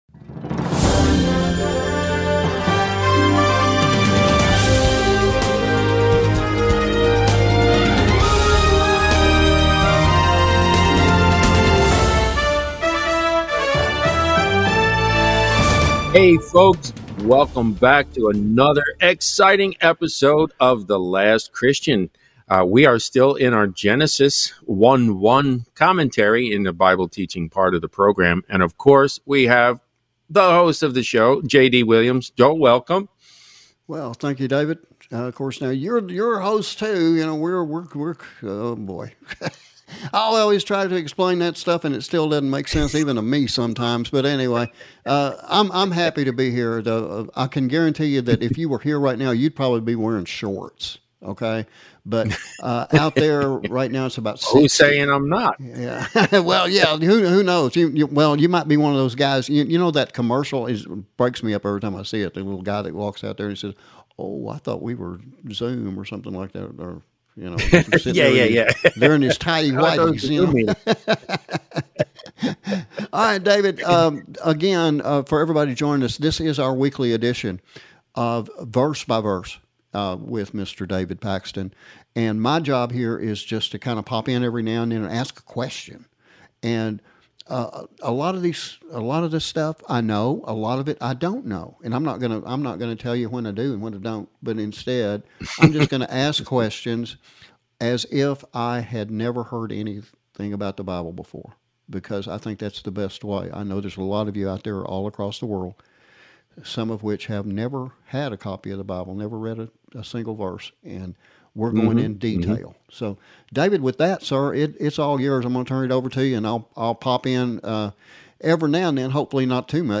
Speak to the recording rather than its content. They present what we believe to be the most in-depth Bible Study available anywhere. Presented every Thursday evening at 7:30pm Central on more than 50 Radio Stations, and broadcast to all 50 US States and mor than 160 Countries around the World.